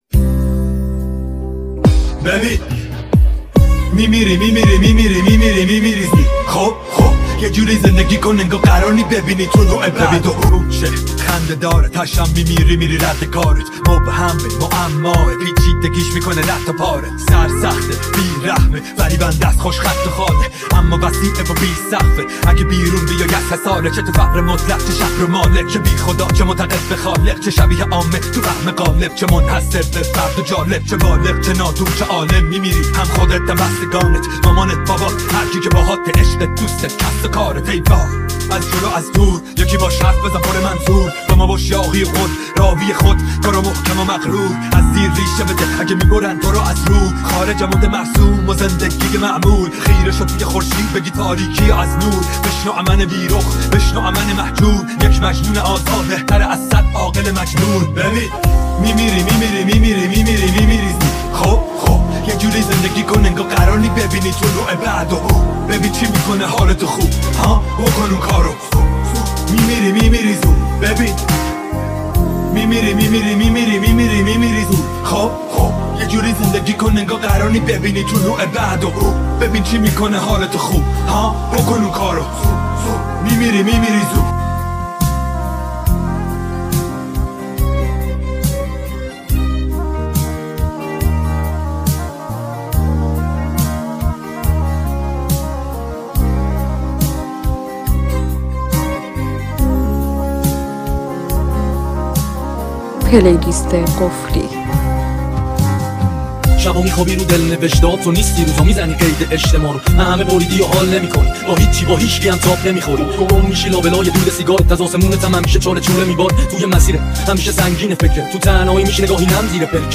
Rap Remix